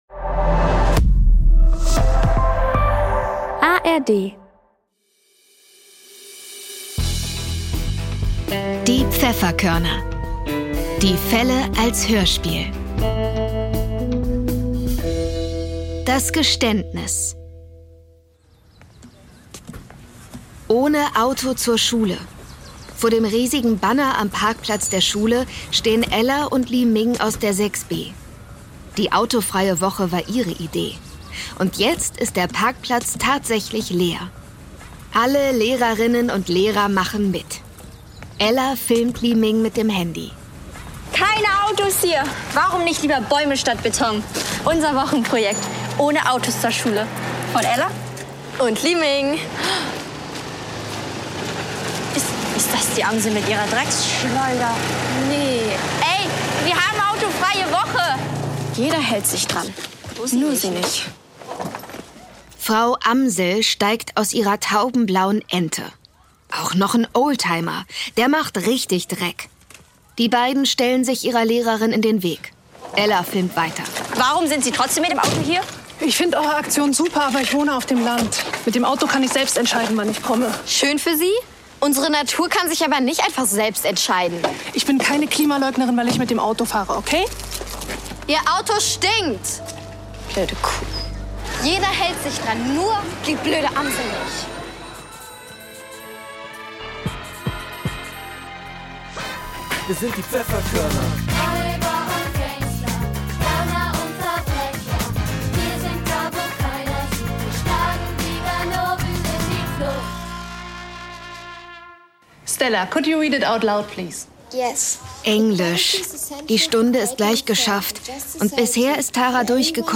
Das Geständnis (7/21) ~ Die Pfefferkörner - Die Fälle als Hörspiel Podcast